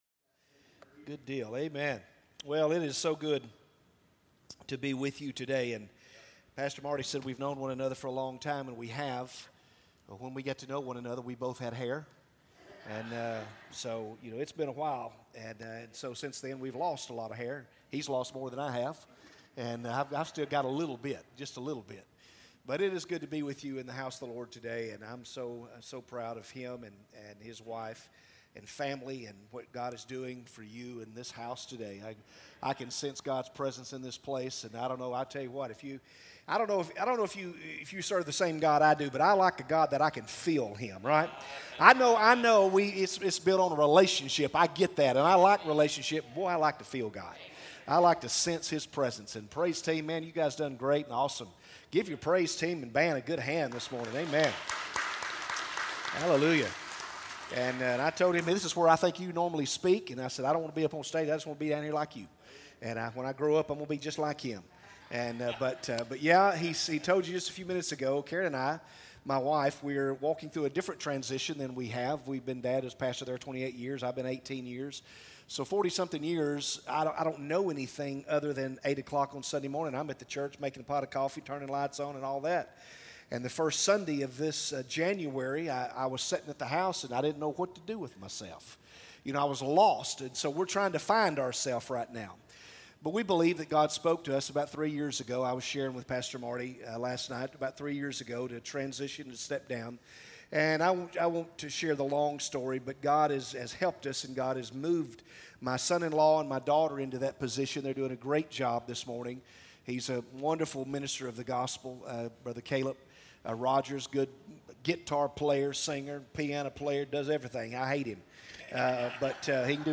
From Series: "Sunday Message"